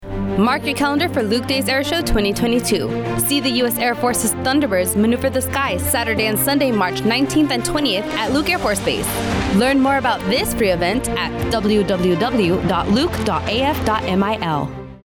15 second radio spot for Luke Days Air Show 2022.